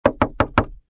GUI_knock_1.ogg